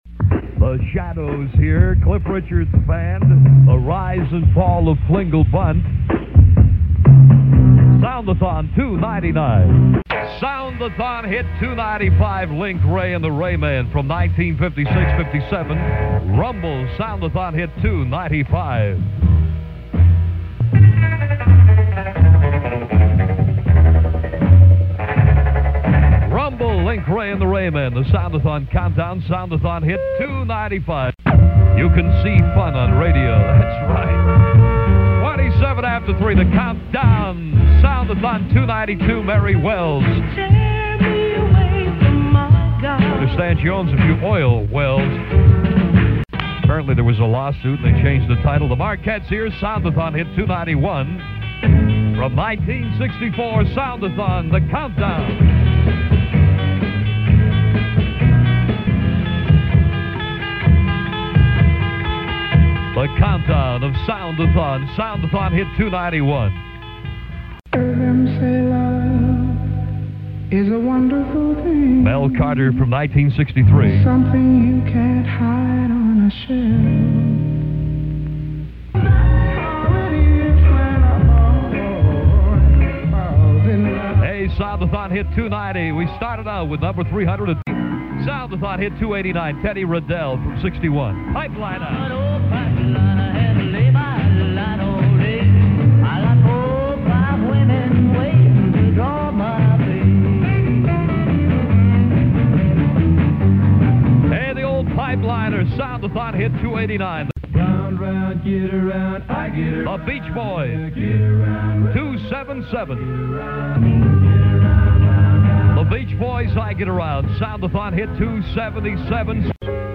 The C-FUN Good Guys introduce the songs in these audio clips recorded
directly from the radio during the actual event in November 1965.
DJs heard in this Montage:
soundathon11montage.mp3